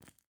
Minecraft Version Minecraft Version latest Latest Release | Latest Snapshot latest / assets / minecraft / sounds / block / candle / ambient2.ogg Compare With Compare With Latest Release | Latest Snapshot